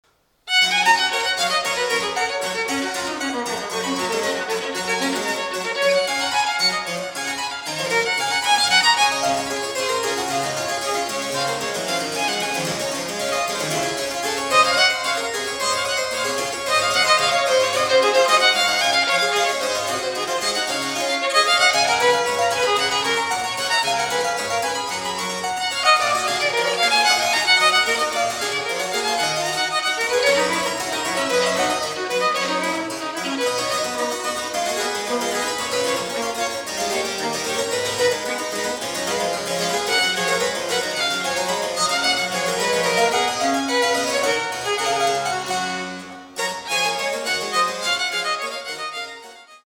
This year, I also participated in the Bachfest as performer in one of the free concerts in the Sommersaal on 6 May at 3 pm, playing
Baroque Violin